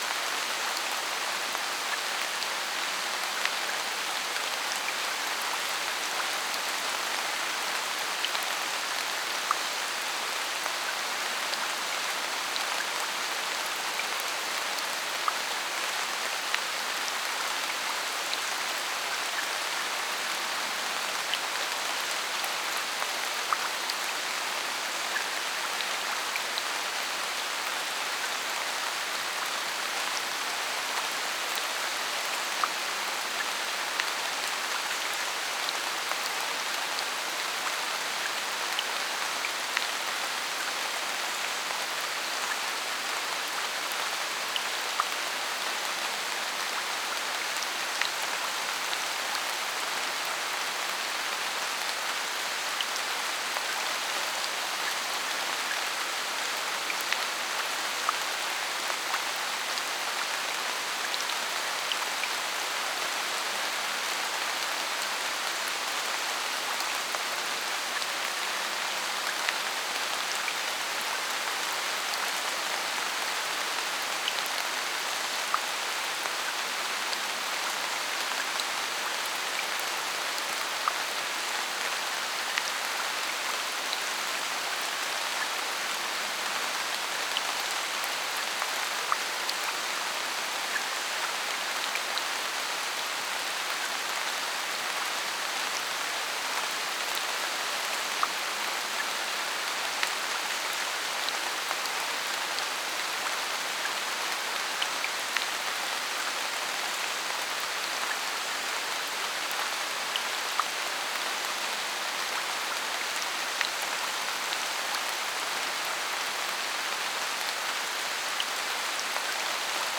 RainingAmbience.wav